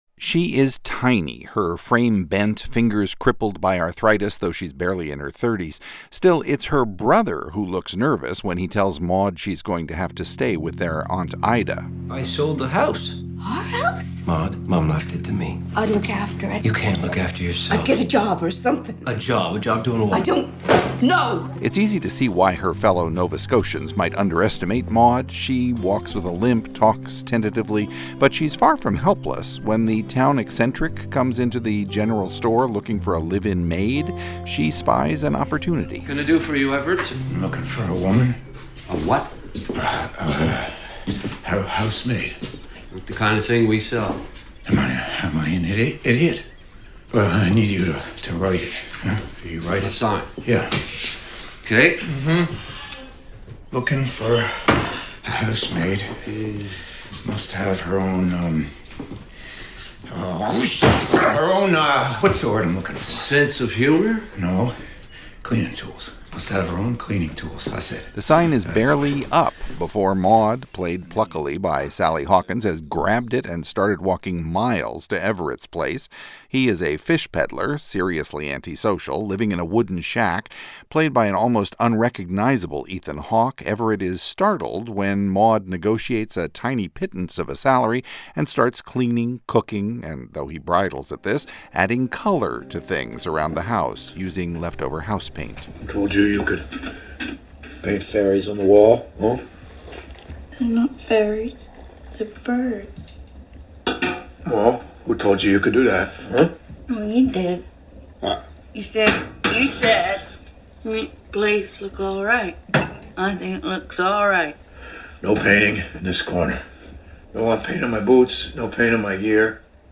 Movie Review